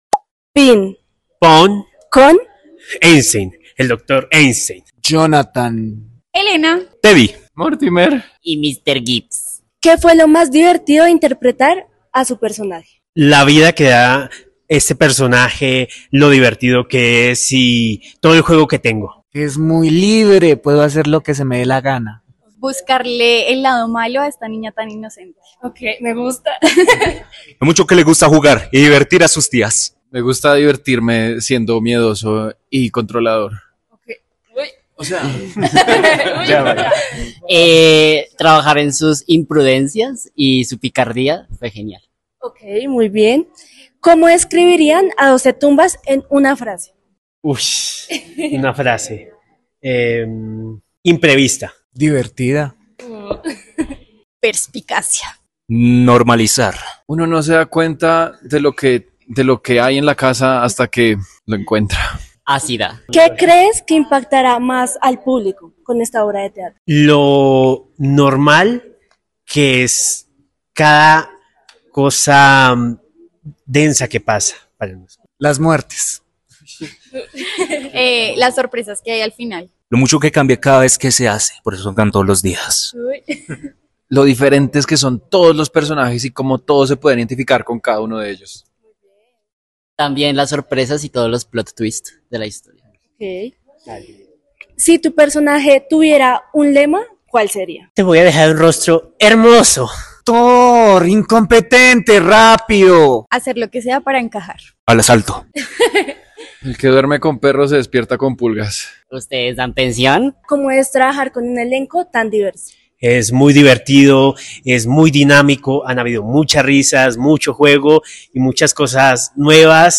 ENTREVISTA DOCE TUMBAS TEATRO NACIONAL